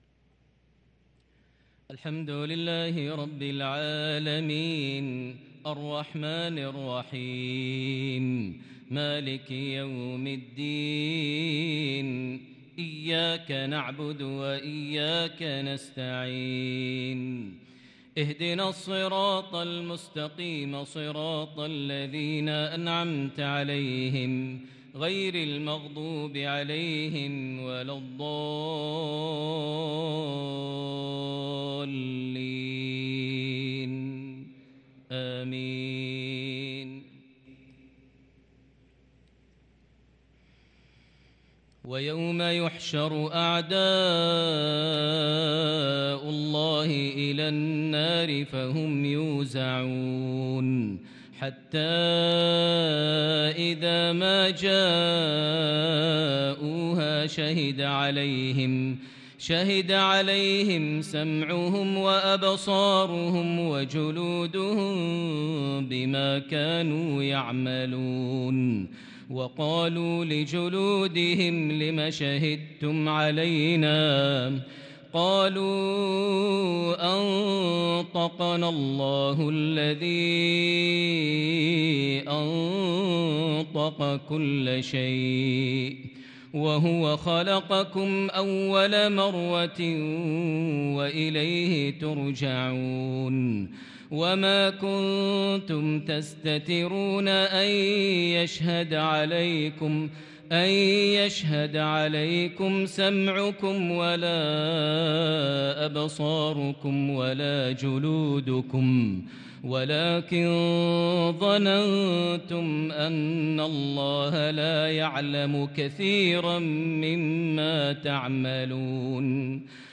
صلاة العشاء للقارئ ماهر المعيقلي 14 ذو القعدة 1443 هـ
تِلَاوَات الْحَرَمَيْن .